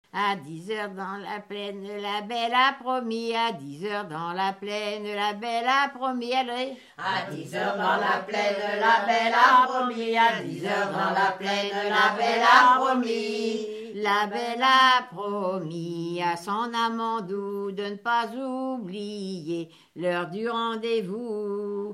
Fonction d'après l'analyste gestuel : à marcher ;
Genre énumérative
Catégorie Pièce musicale inédite